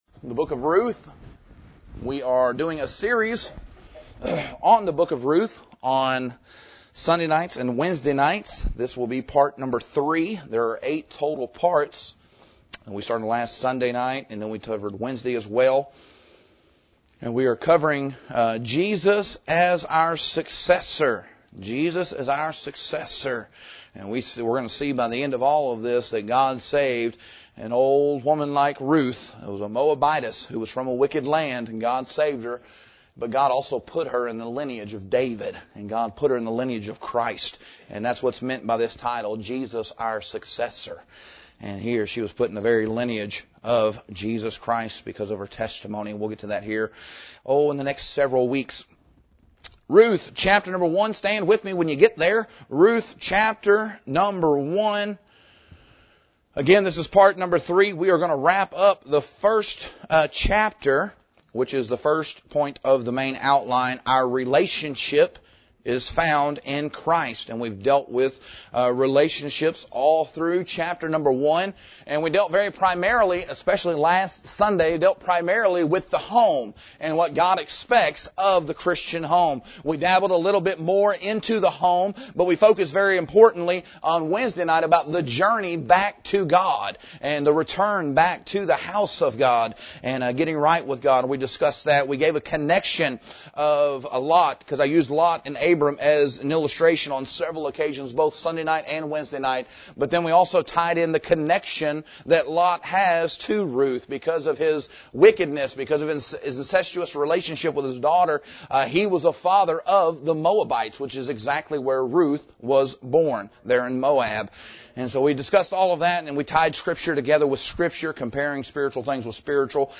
Introduction Sermon Title